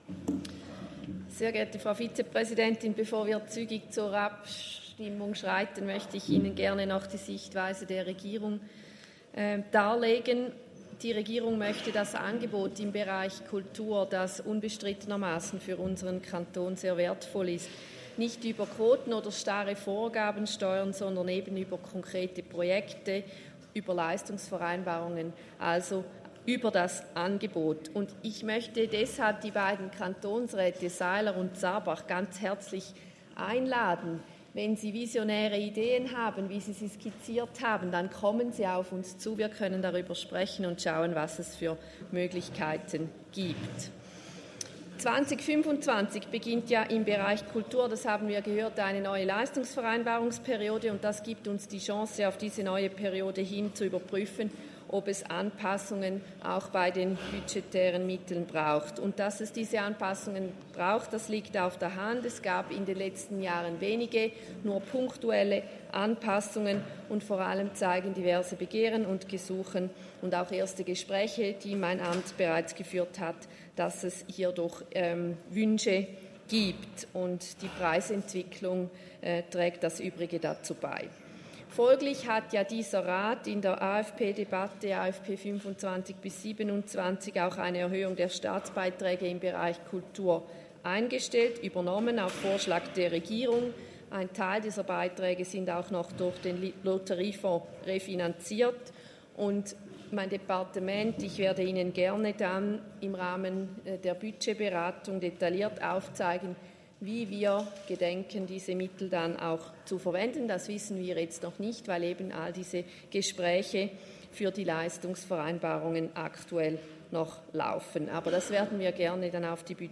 Session des Kantonsrates vom 29. April bis 2. Mai 2024, Aufräumsession
1.5.2024Wortmeldung
Regierungsrätin Bucher: Auf die Motion ist nicht einzutreten.